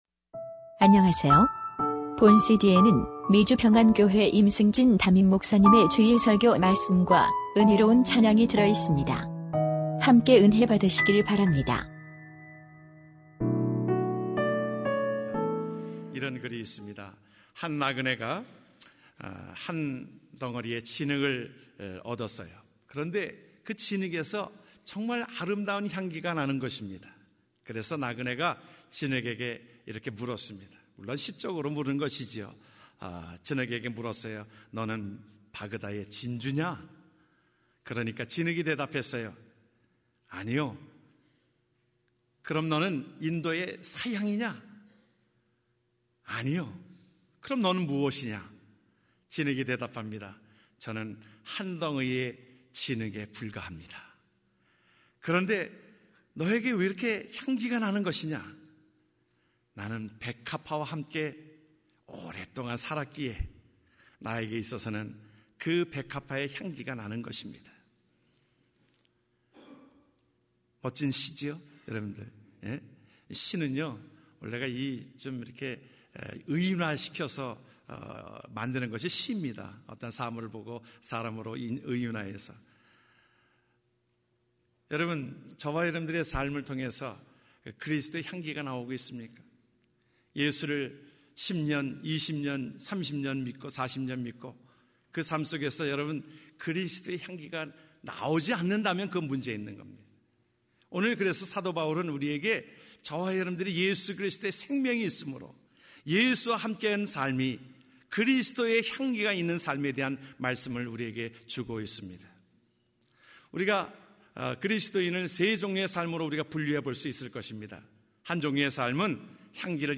2014년 11월 2일 주일설교말씀: 그리스도의 향기가 있는 삶 (고린도후서 2:12-17)